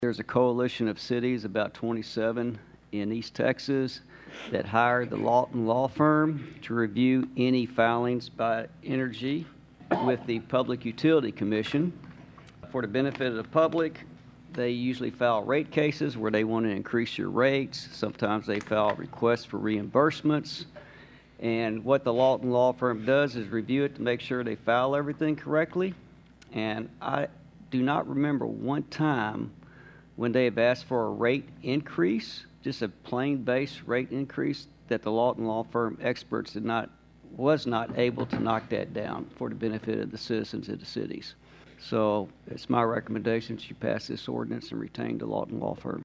City Attorney Leonard Schneider explained to councilmembers.